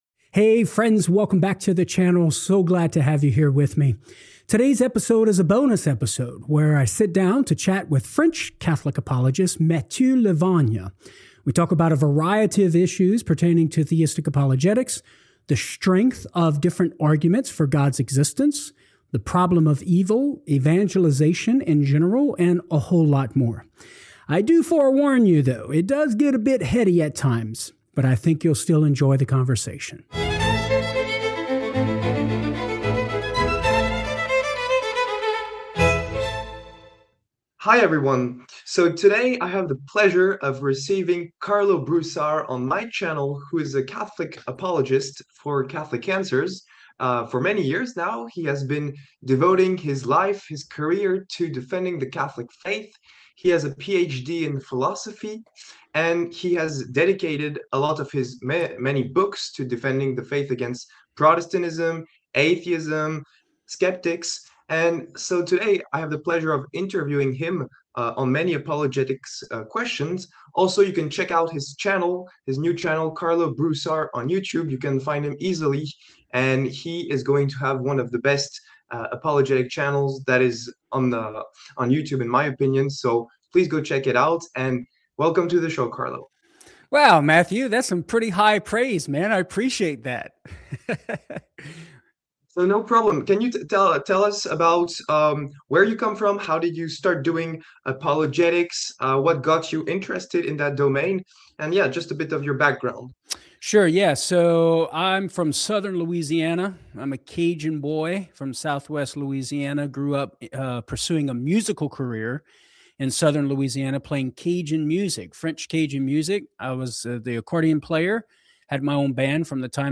And so today I have the pleasure of interviewing him on many apologetics questions.